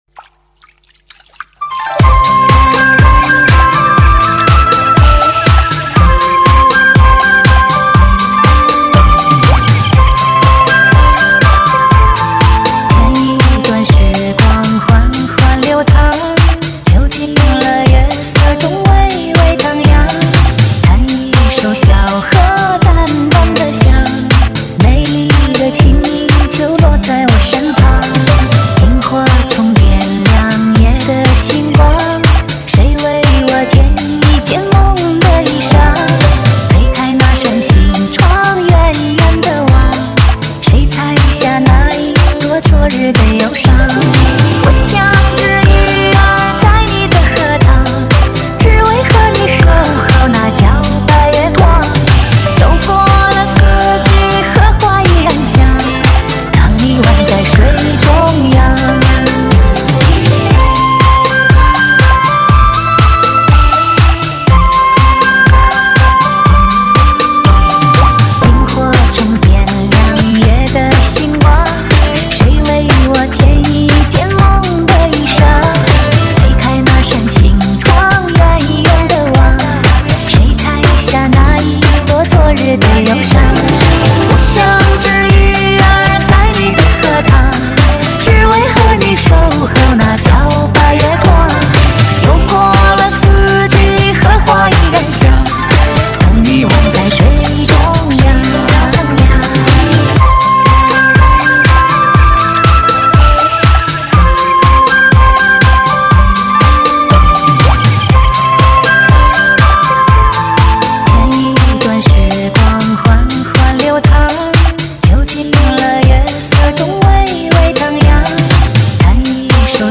类型: 汽车音乐